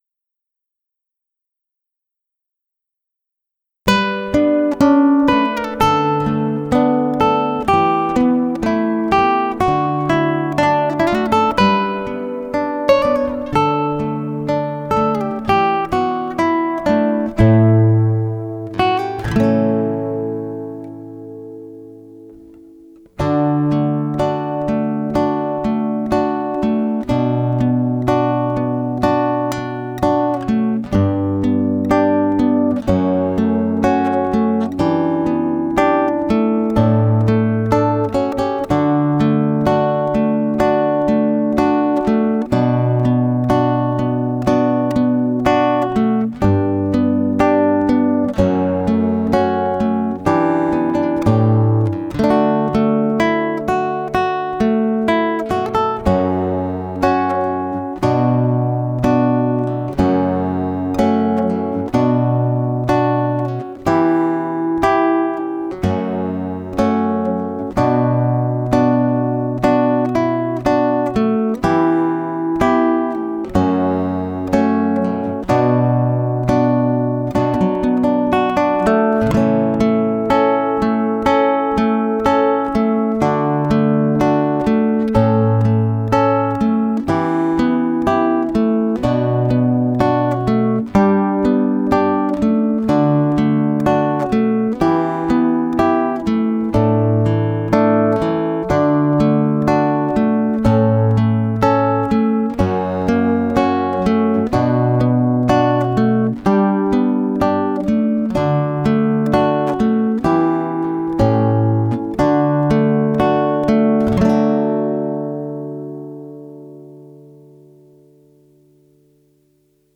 D调古典吉他伴奏